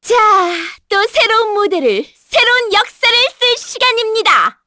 Shamilla-Vox_Skill6_kr.wav